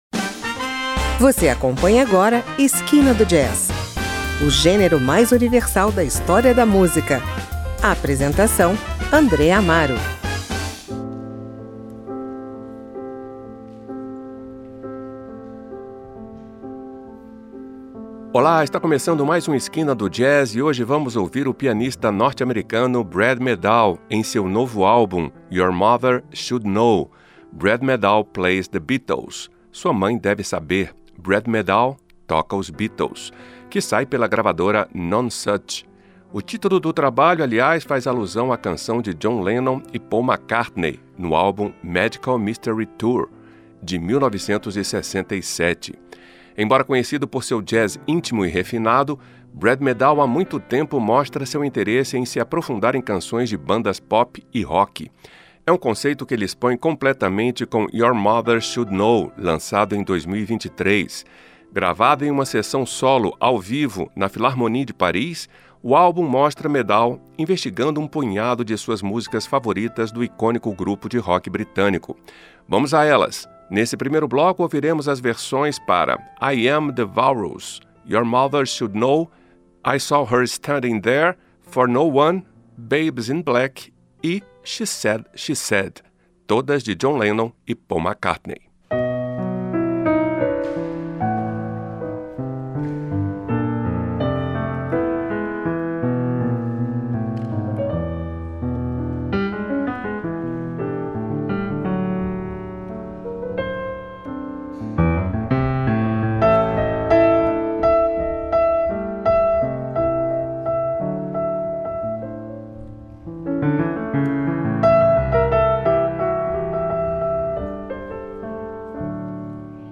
Embora conhecido por seu jazz íntimo e refinado